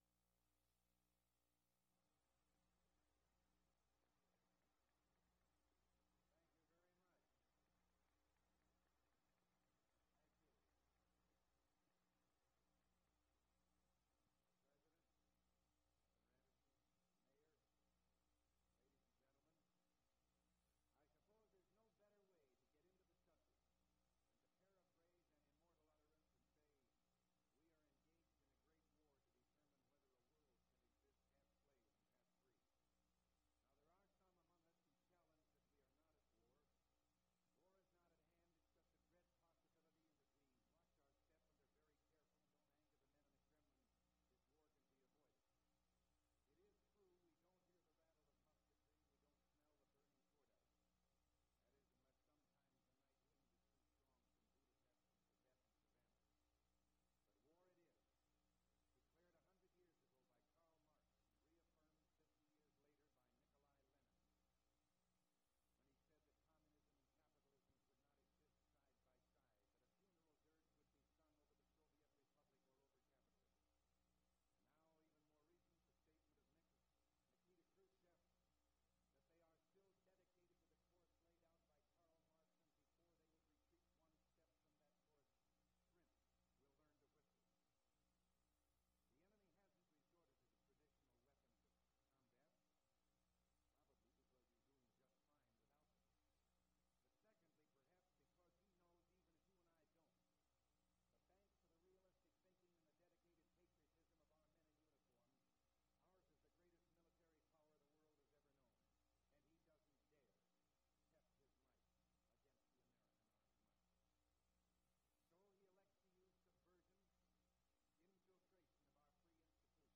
“The Speech” Ronald Reagan’s speech in St. Paul, Minnesota
Audio Cassette Format.